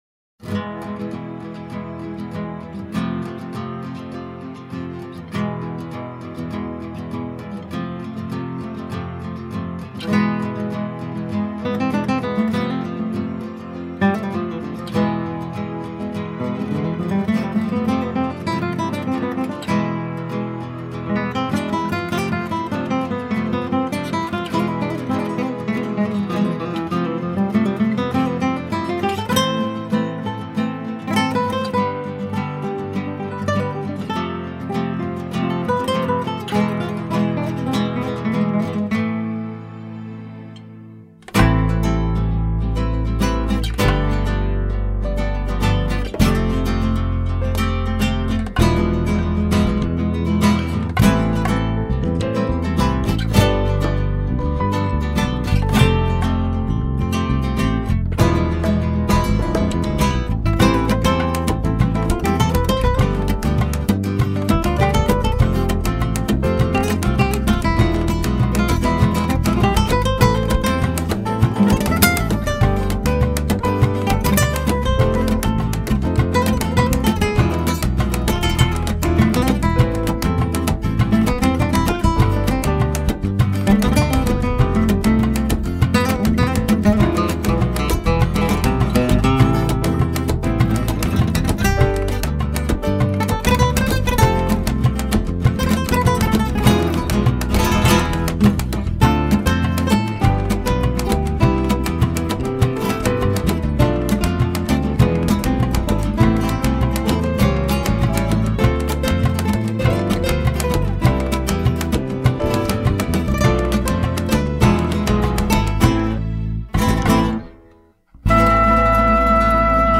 328   06:22:00   Faixa:     Instrumental
Violão tenor
Acoordeon
Bateria
Percussão
Guitarra